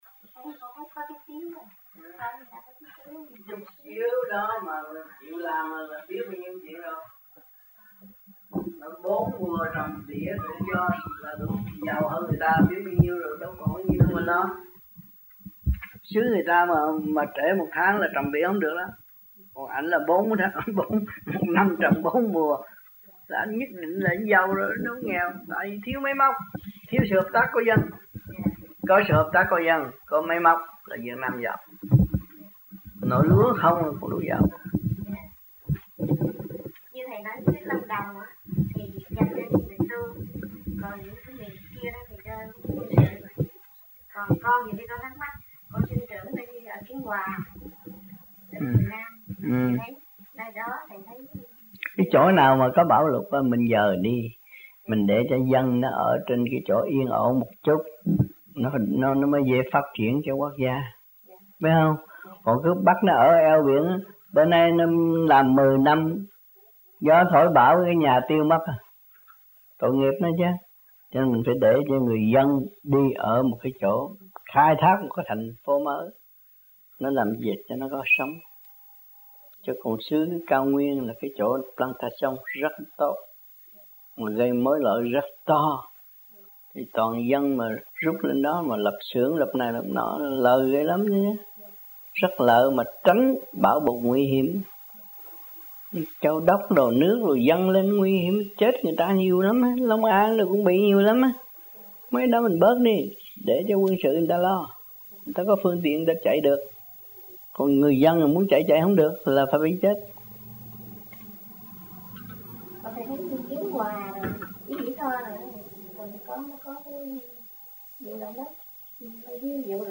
1992 Khóa Học Tại Thìền Viện Vĩ Kiên